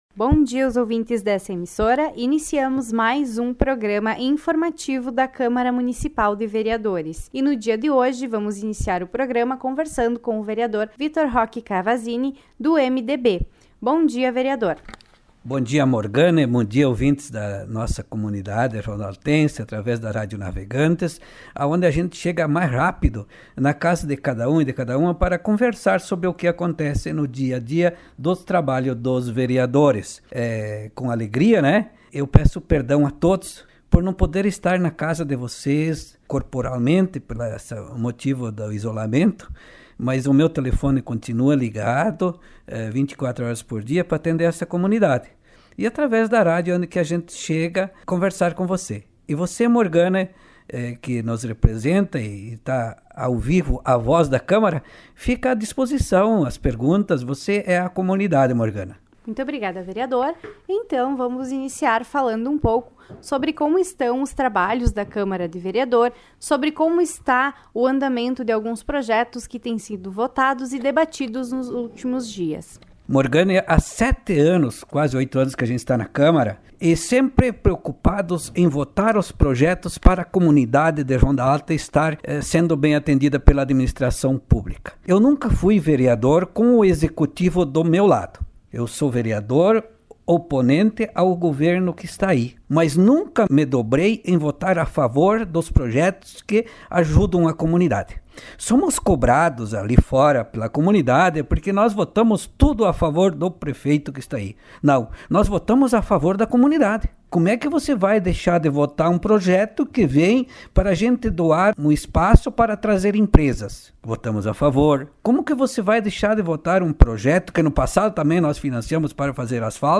Informativos radiofônicos